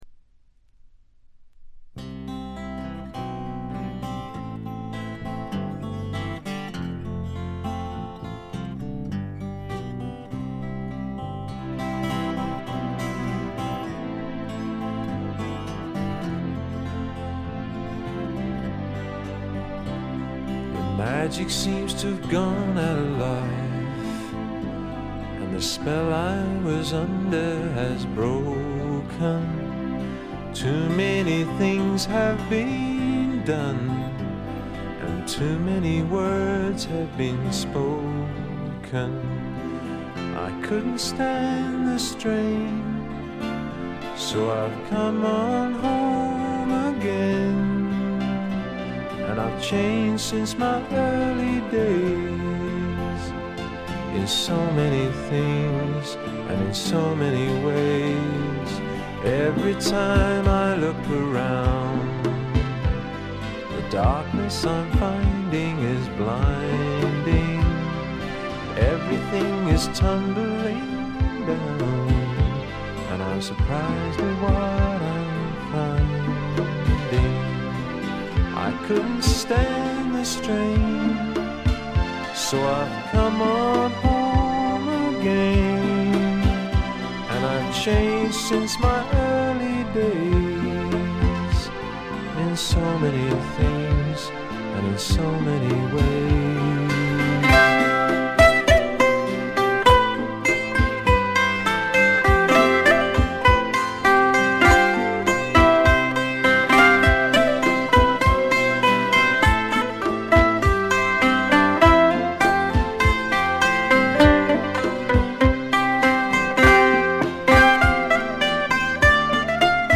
ごくわずかなノイズ感のみ。
試聴曲は現品からの取り込み音源です。
Vocals Guitars
Keyboards
Balalaika
Bass Vocals
Recorded at Turboways Studio, Lonbdon 1985/6.